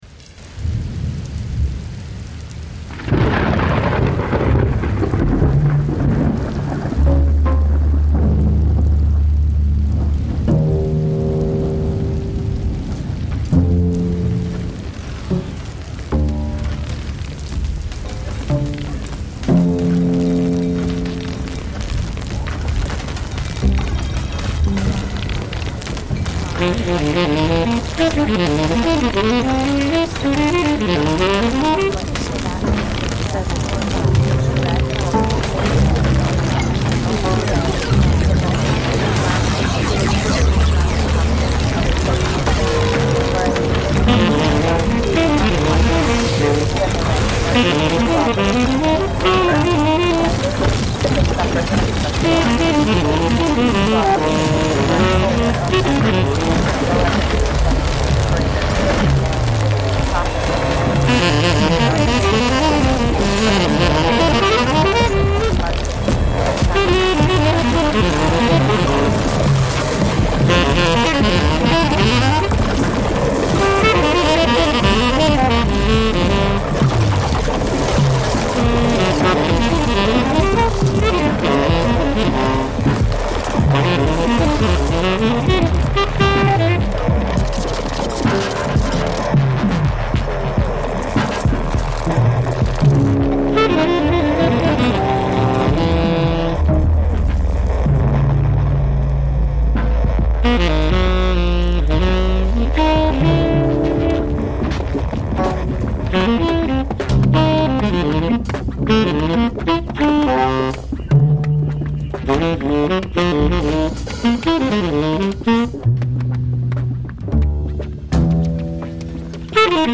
live streamed on 27 December 2024
Saxophone
Additional (live mashed) sonic fictions
a late night live streamed music show with teeth (and horns)